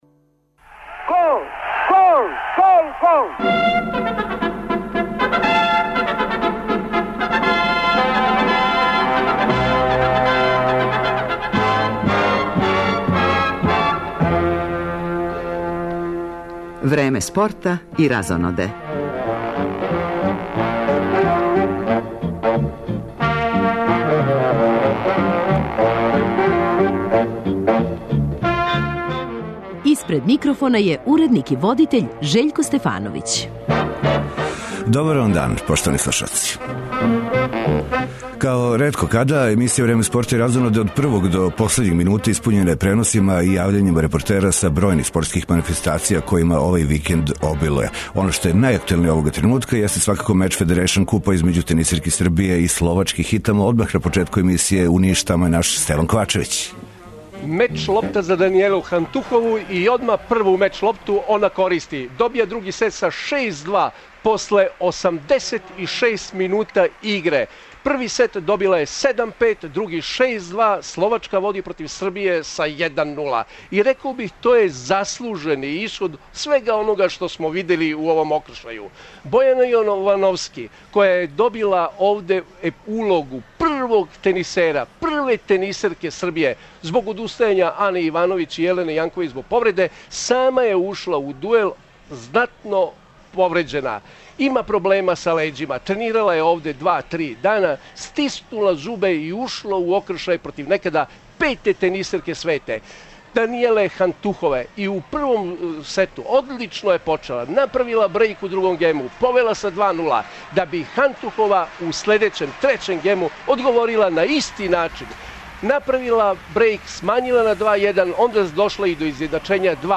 Као ретко када, емисија Време спорта и разоноде од првог до последњег минута испуњена је преносима и јављањима репортера са бројних спортских манифестација, којима овај викенд обилује. Из Ниша пратимо меч Федерејшн купа, између тенисерки Србије и Словачке.